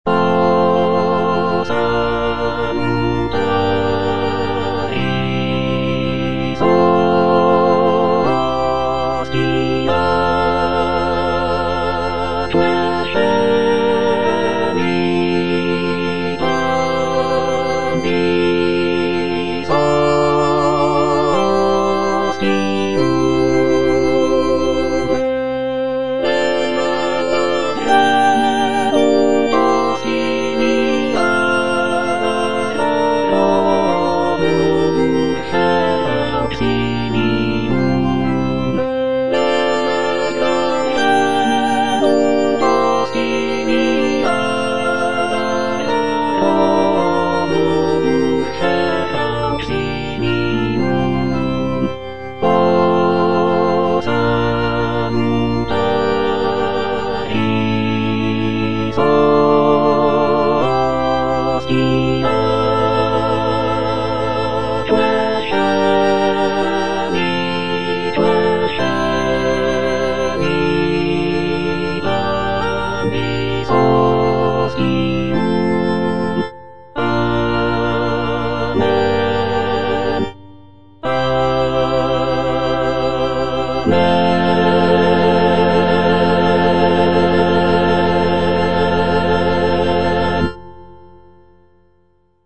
T. DUBOIS - MESSE IN F O salutaris hostia (All voices) Ads stop: auto-stop
It is a setting of the traditional Catholic Mass text in the key of F major. The piece is known for its lush harmonies, intricate counterpoint, and lyrical melodies.